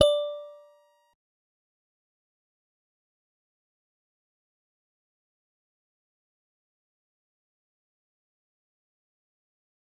G_Musicbox-D6-mf.wav